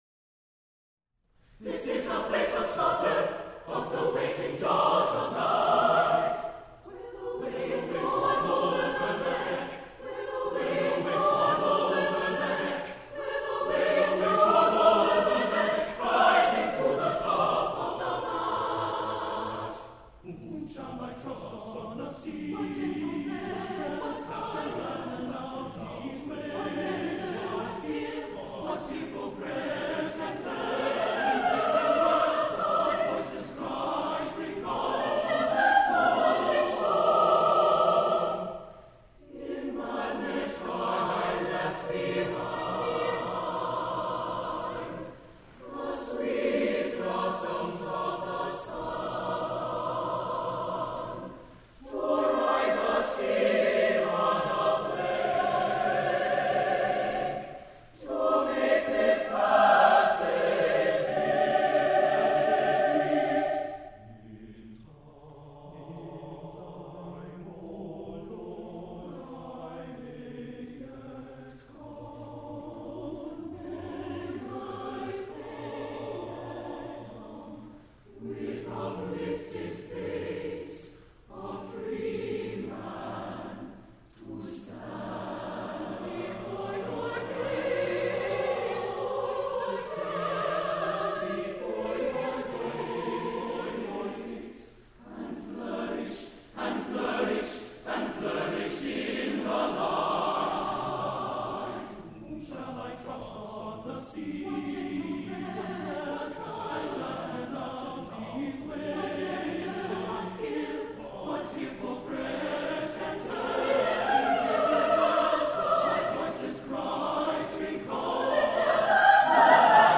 for SATB chorus a cappella - 1st mvt